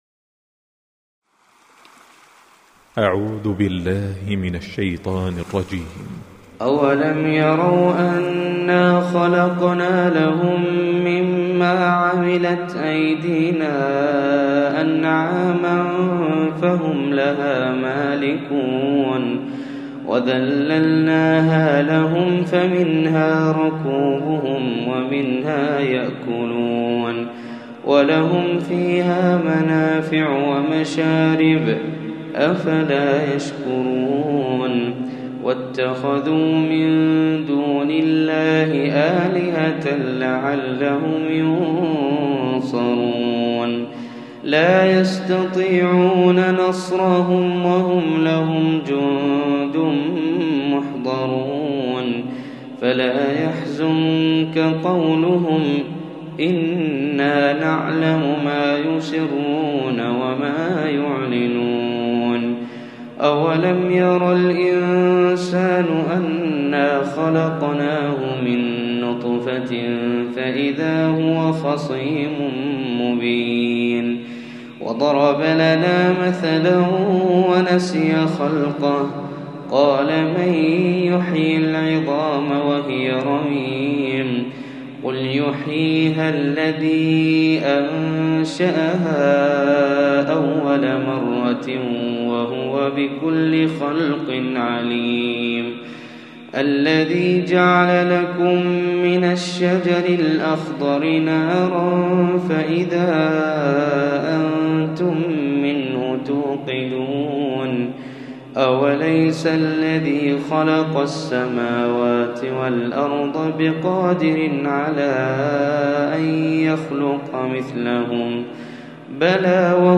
Recitime